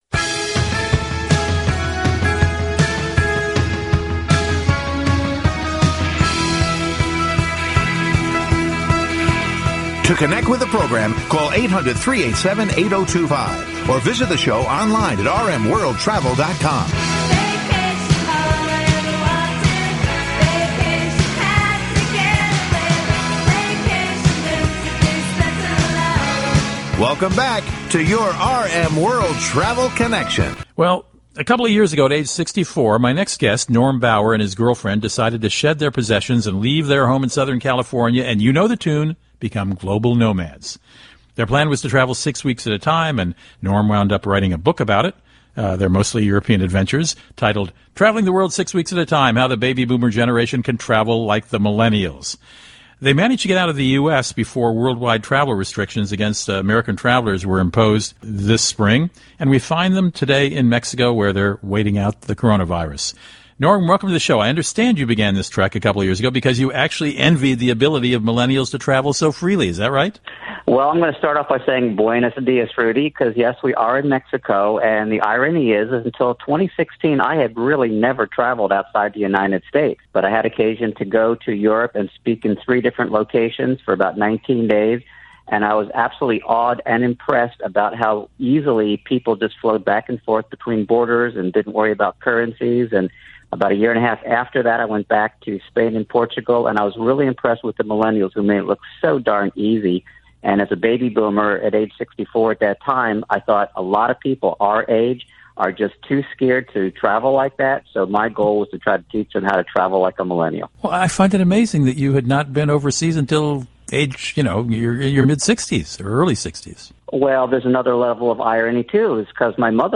This is a brief interview with a nationally syndicated travel radio show.